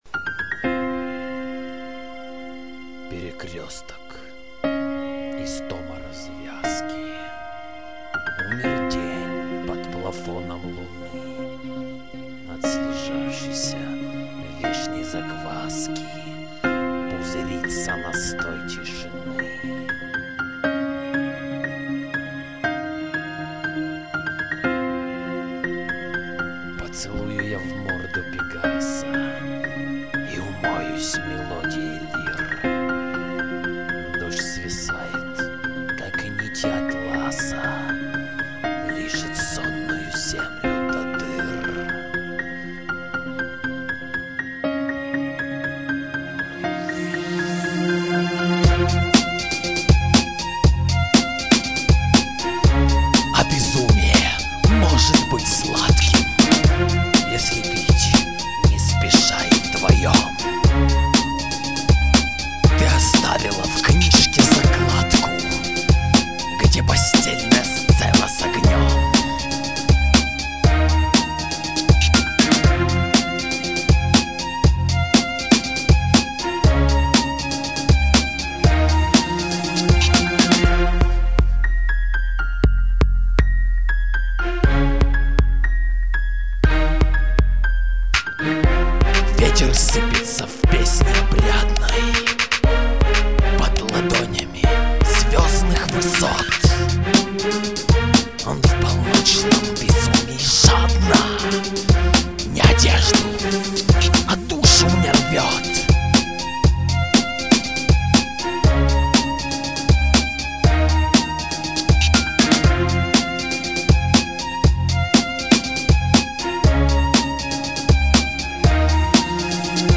К чему такой безудержный пафас?